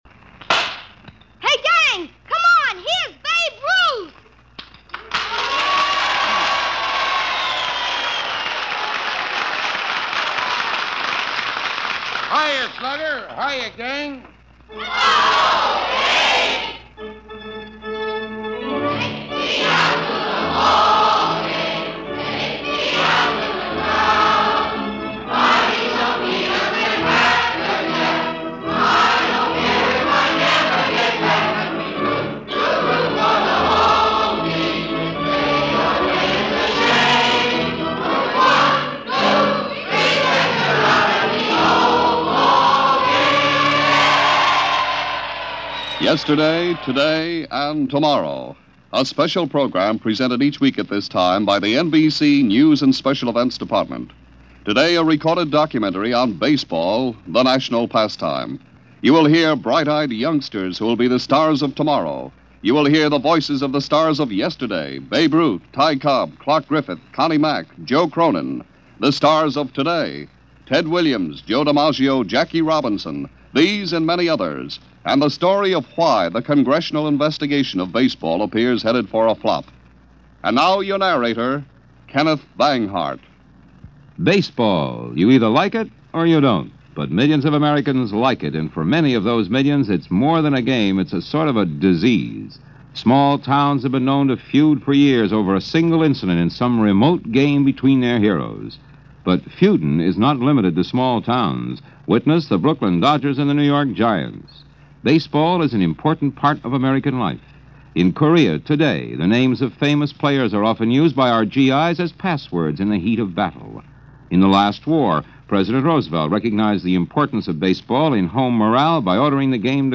So to commemorate that yearly ritual, here is a documentary produced by NBC Radio in 1951. Part of their Yesterday, Today and Tomorrow series entitled simply: Baseball.
It’s a historic look back at the game – with interviews and excerpts – featuring legends such as Babe Ruth , Connie Mack , Jackie Robinson and many others.